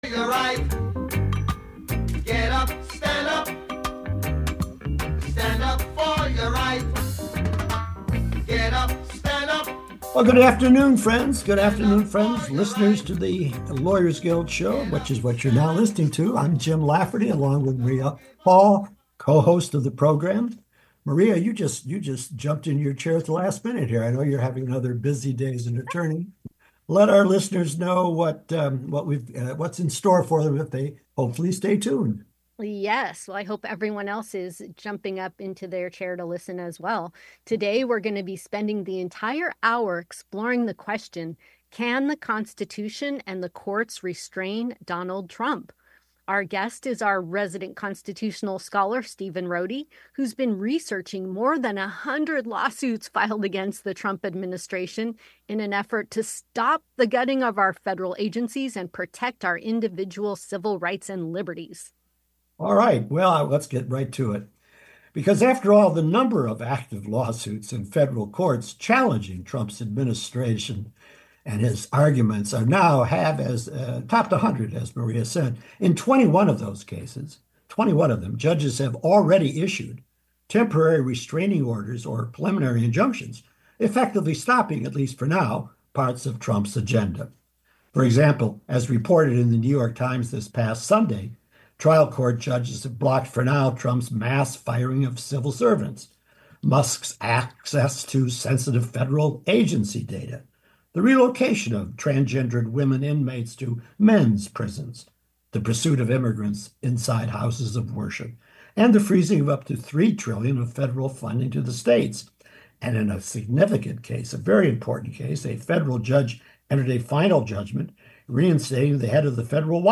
A public affairs program where political activists and experts discuss current political developments and progressive movements for social change.